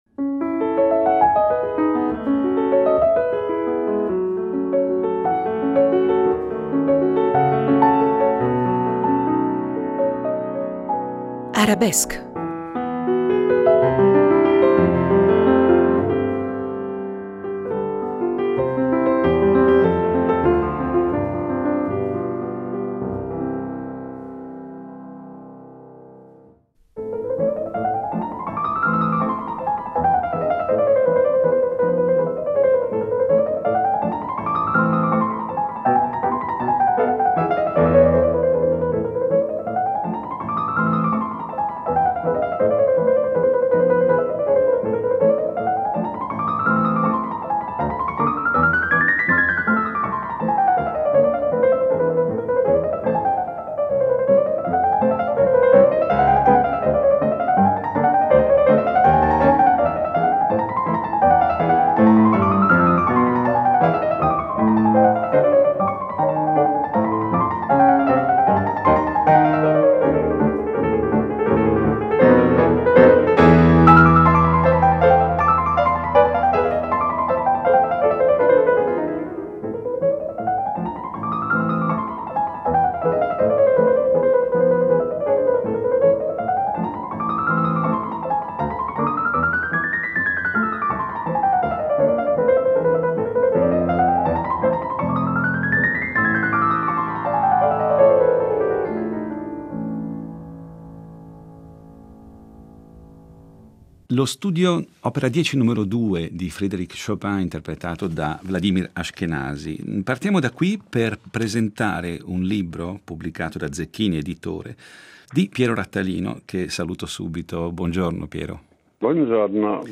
Intervista con Piero Rattalino